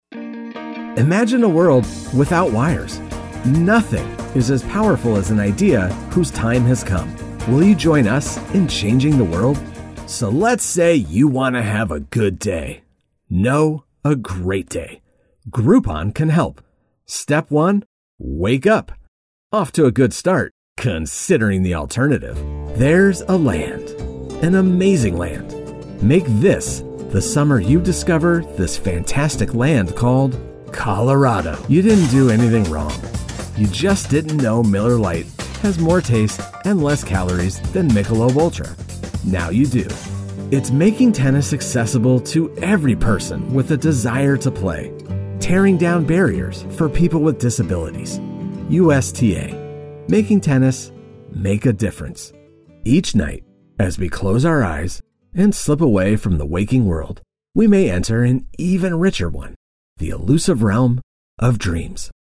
Male Voice Over Talent, Artists & Actors
Adult (30-50)